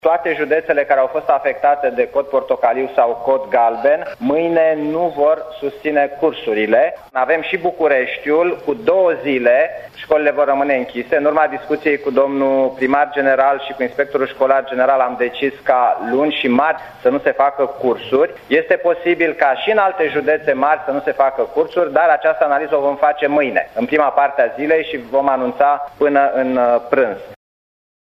După o evaluare a situaţiei la Palatul Victoria, şeful Guvernului a avertizat că şi săptămâna viitoare ne vom confrunta cu vreme rea. Victor Ponta: